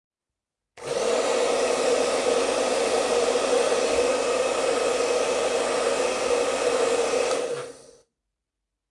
标签： 抽象 电源-off
声道立体声